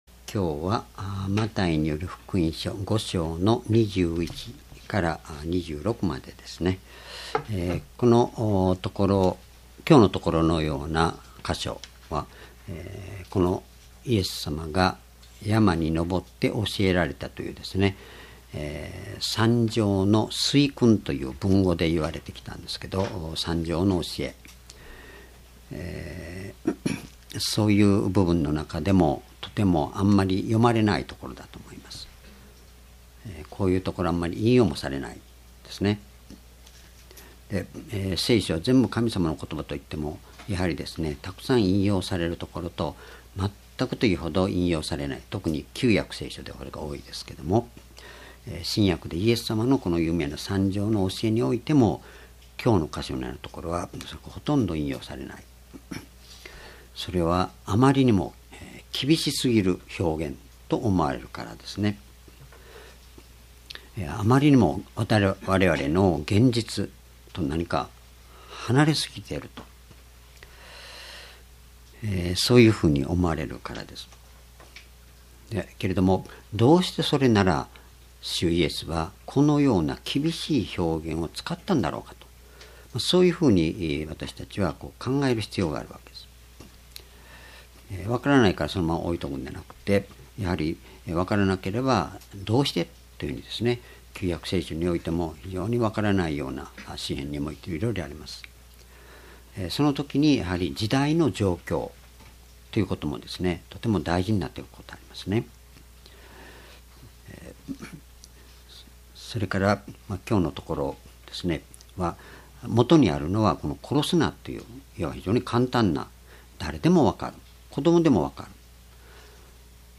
主日礼拝日時 ２０１６年６月１９日 聖書講話箇所 マタイ福音書5章21-26 「人間同士の心のあり方と神の裁き」 ※視聴できない場合は をクリックしてください。